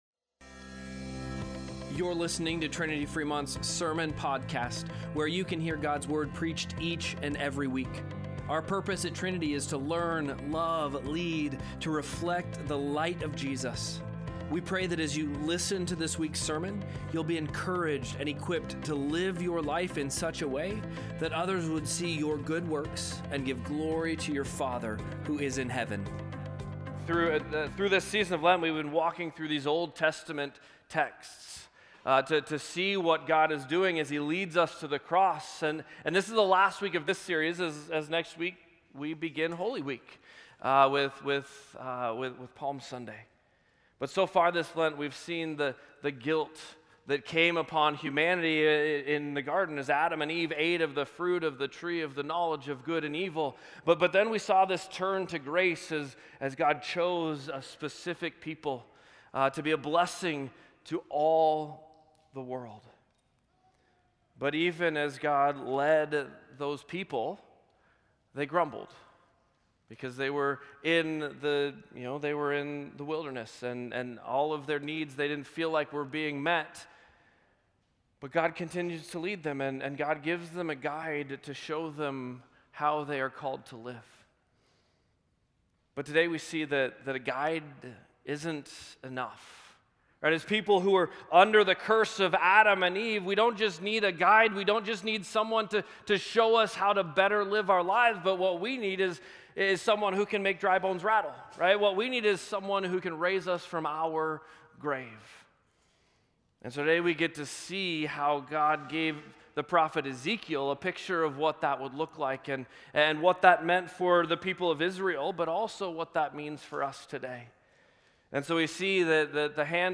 3-22-Sermon-Podcast.mp3